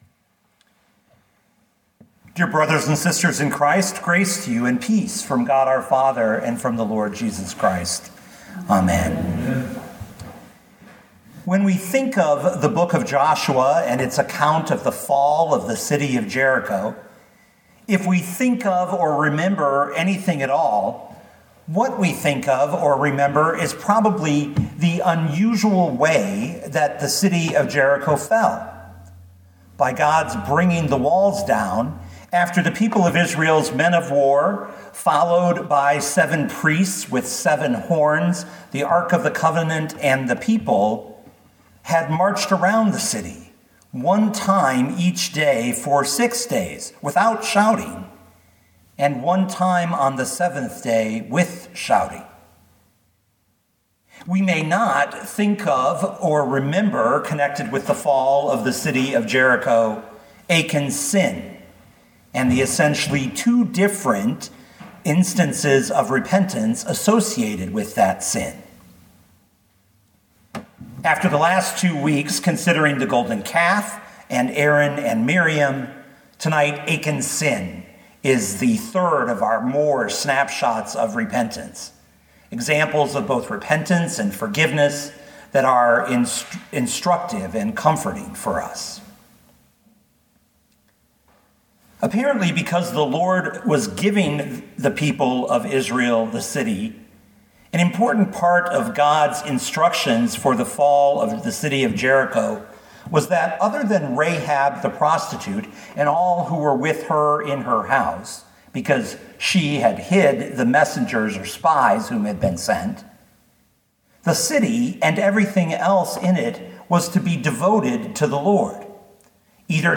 2022 Joshua 7:1-26 Listen to the sermon with the player below, or, download the audio.